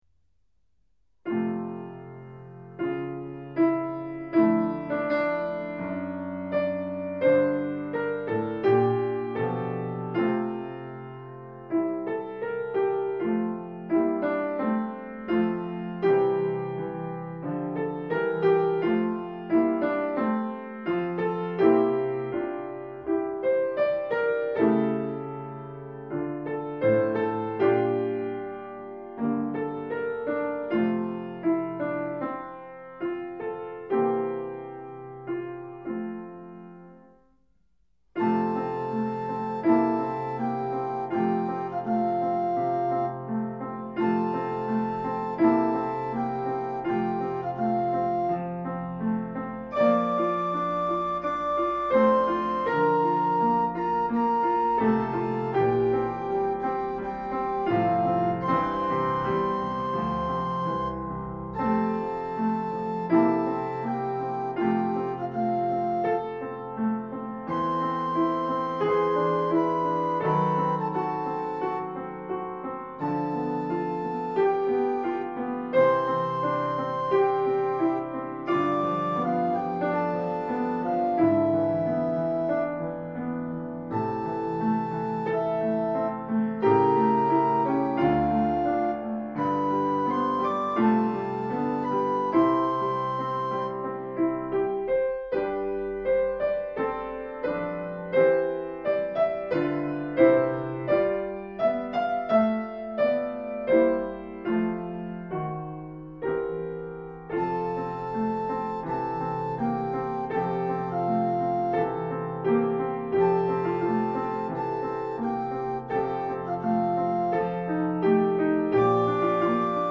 A.A. 24/25 Canto Corale